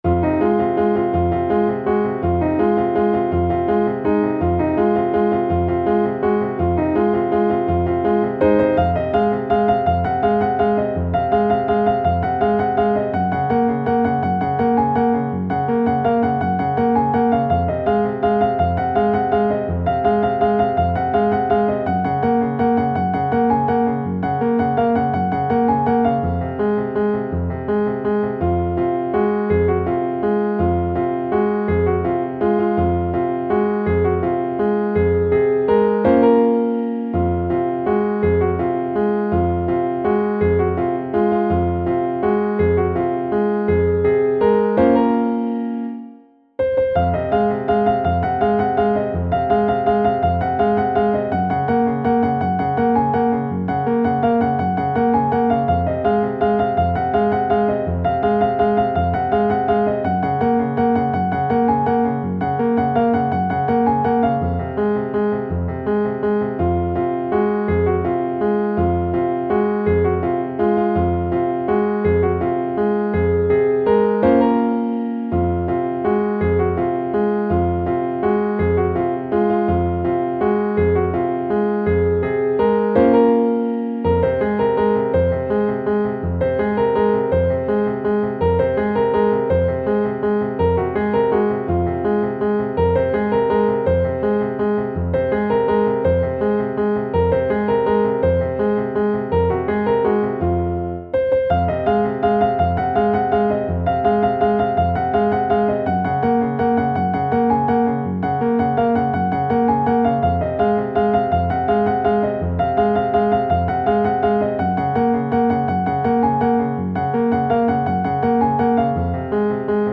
نت پیانو